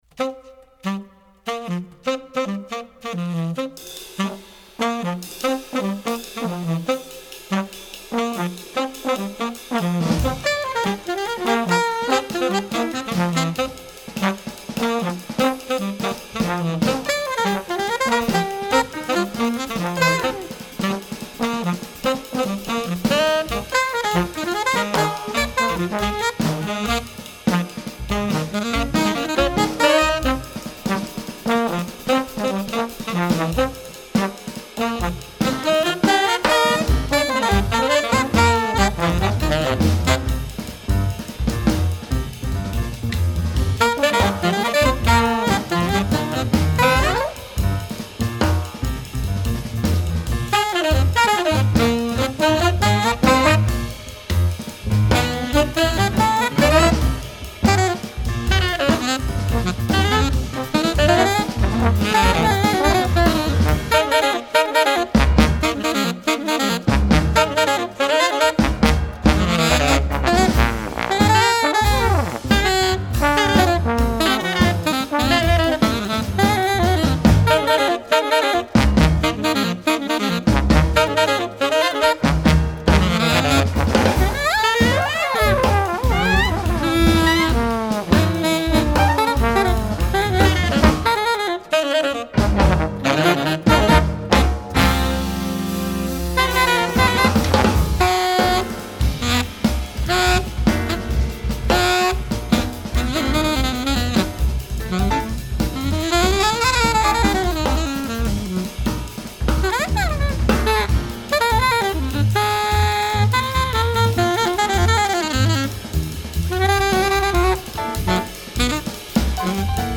Voicing: Combo Sextet